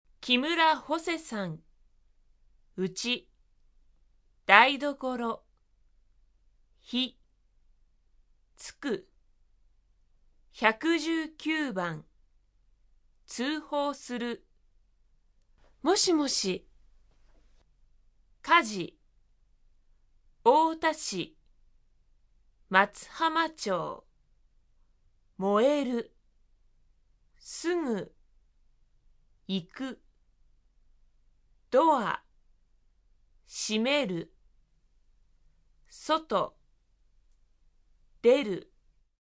電話（自宅）